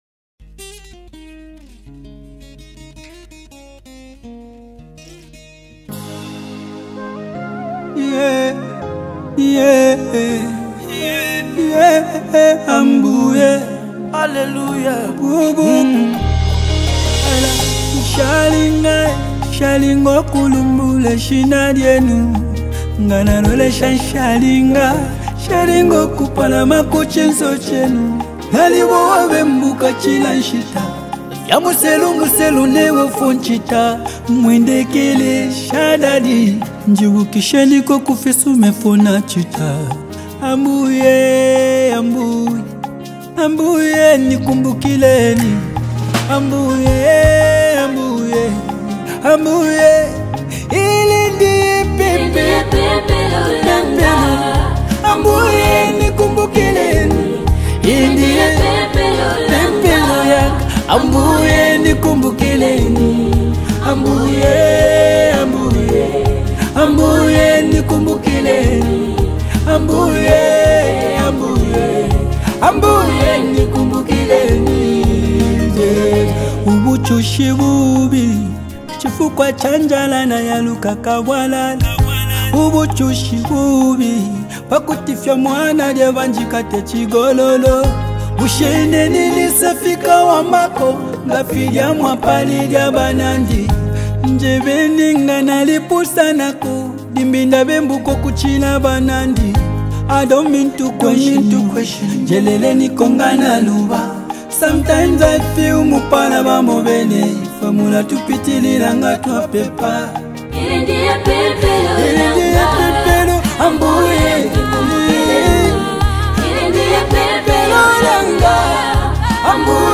Afro-pop and R&B
exceptional vocal delivery